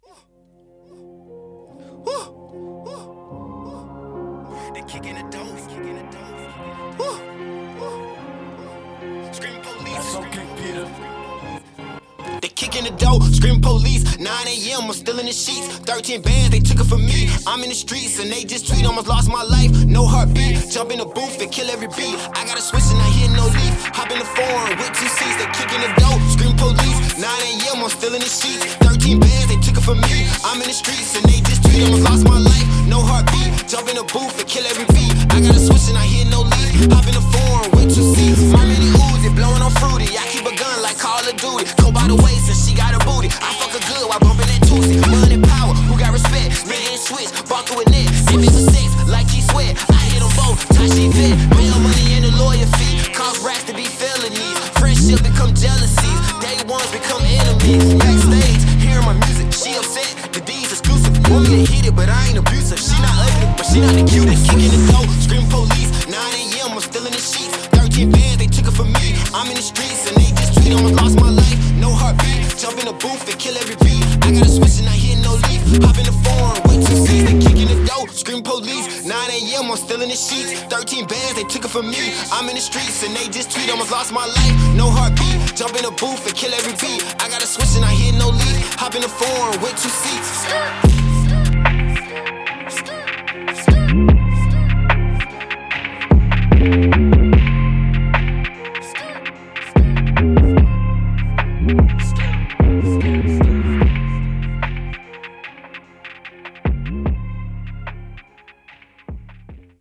Rap
hiphop